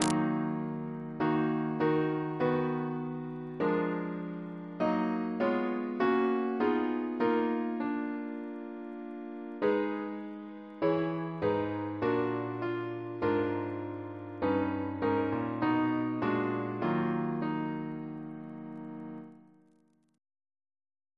Double chant in E♭ minor Composer: Sir H. Walford Davies (1869-1941), Organist of the Temple Church and St. George's, Windsor Reference psalters: ACP: 321